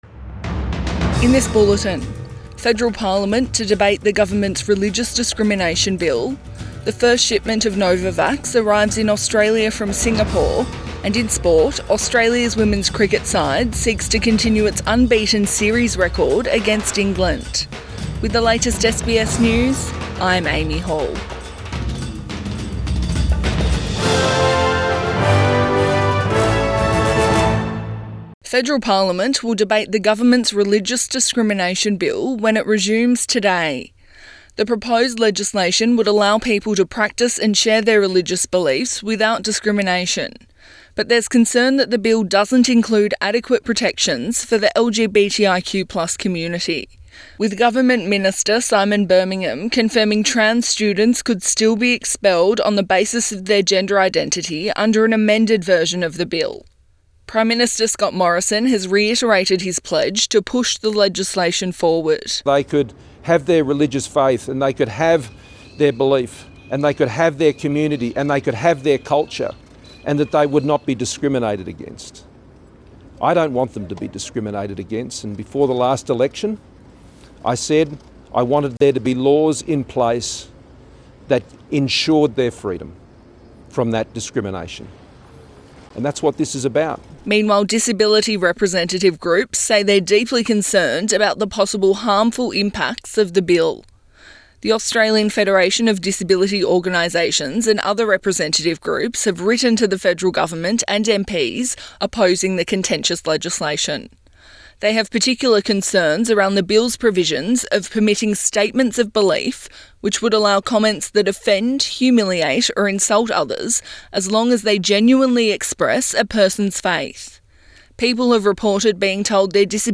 Midday bulletin 8 February 2022